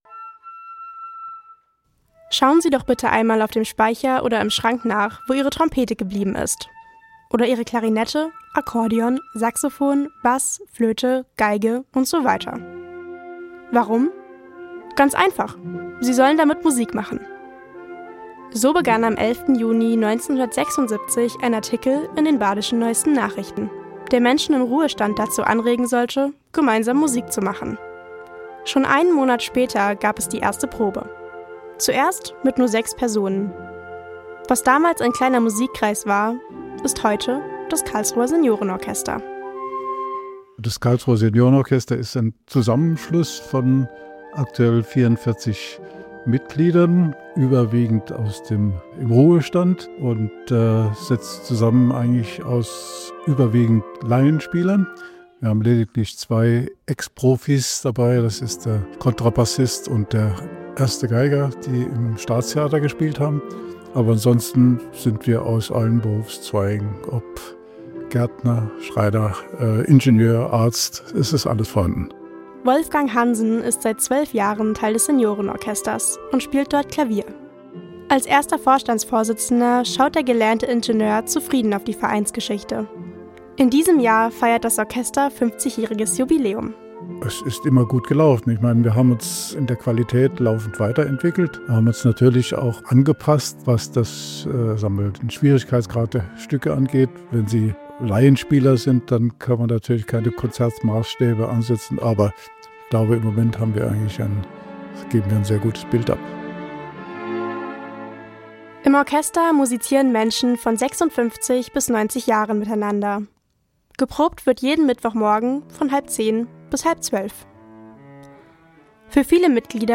Probenbesuch im Karlsruher Seniorenorchester